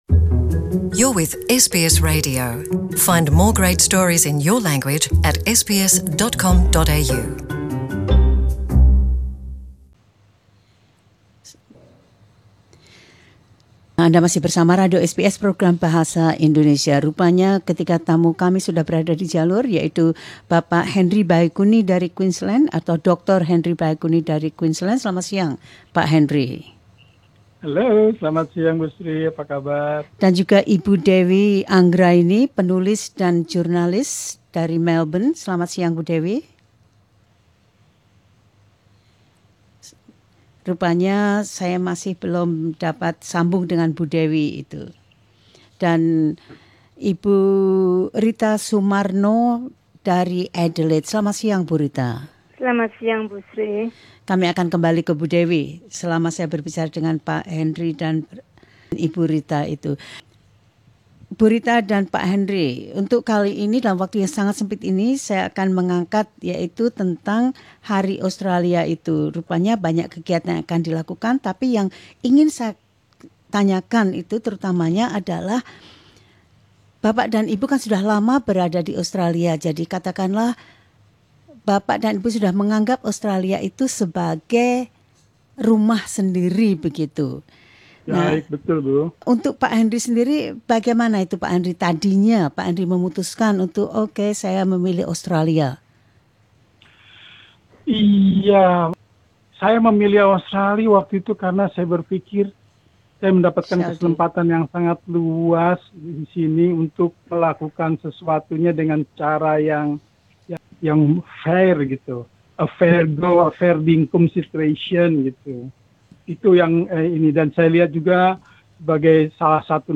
Ketiga orang Indonesia yang telah cukup lama tinggal di Australia itu berbagi pendapat mereka.